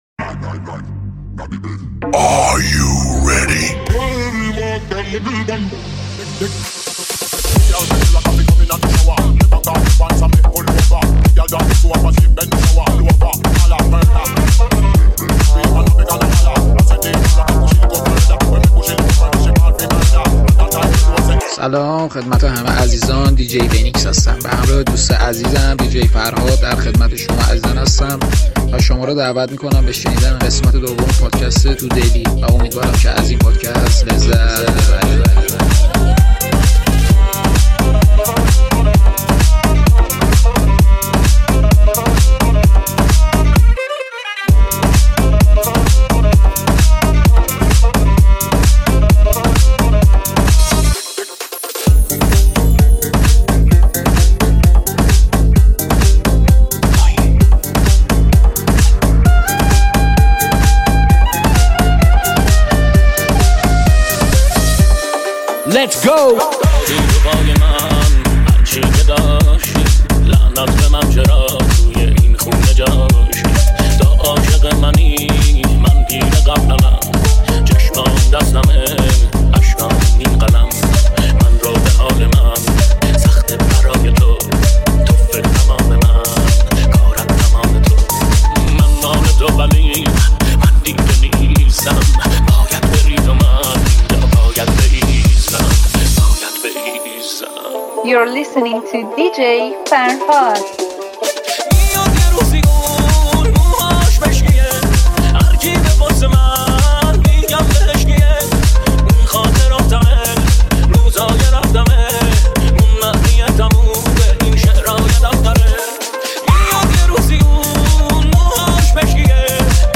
سفری بی‌نظیر در دنیای موسیقی با میکس‌های شنیدنی و پرانرژی.